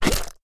Minecraft Version Minecraft Version snapshot Latest Release | Latest Snapshot snapshot / assets / minecraft / sounds / mob / slime / attack1.ogg Compare With Compare With Latest Release | Latest Snapshot